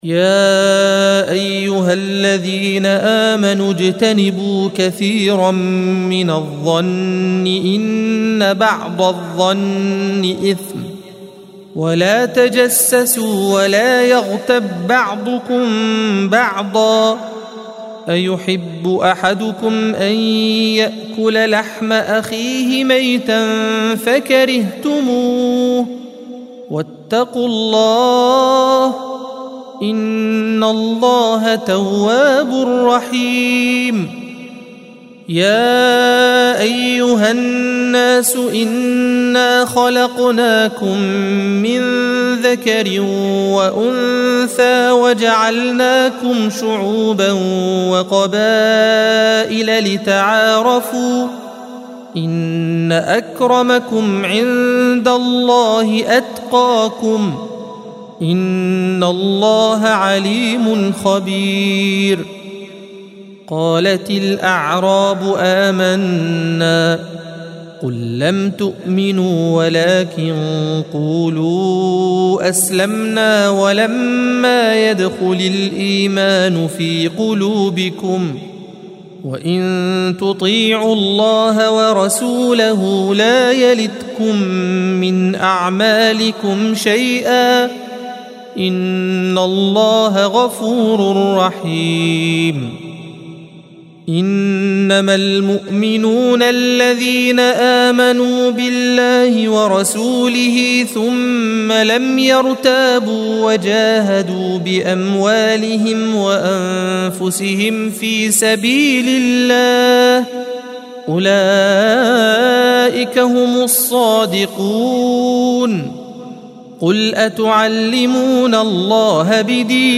الصفحة 517 - القارئ